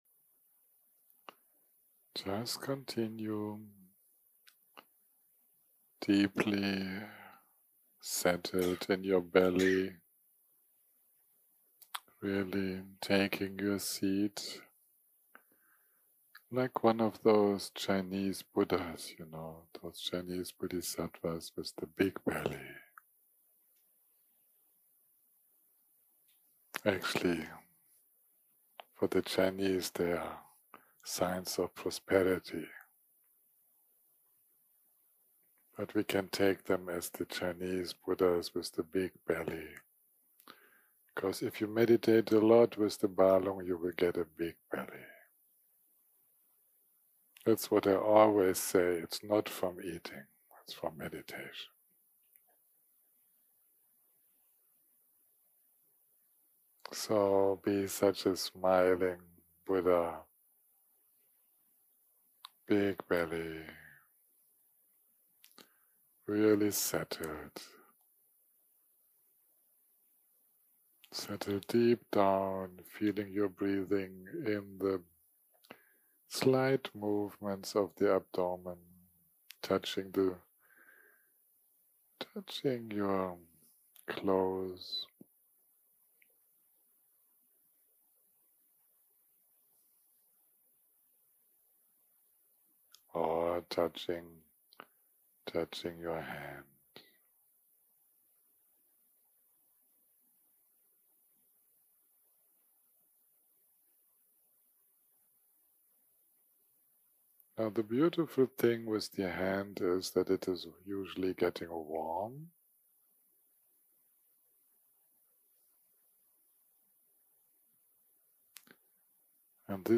יום 6 - הקלטה 29 - ערב - מדיטציה מונחית